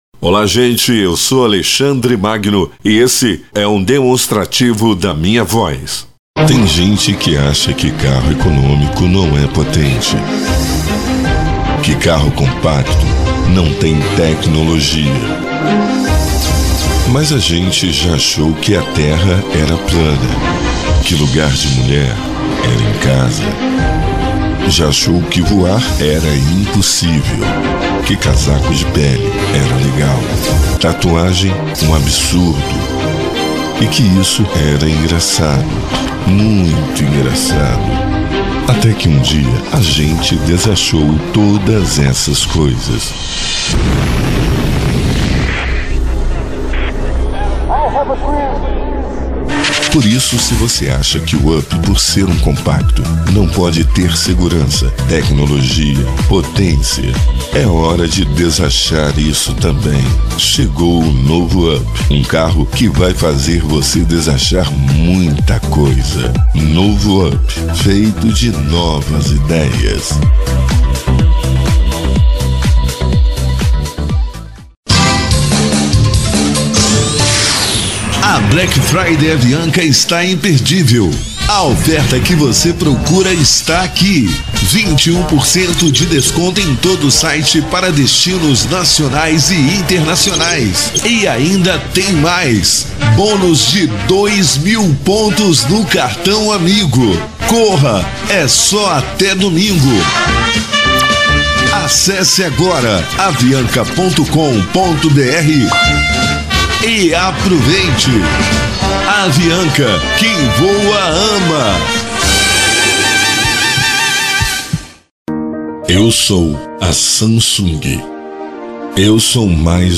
Estilo(s): PadrãoImpacto Animada Varejo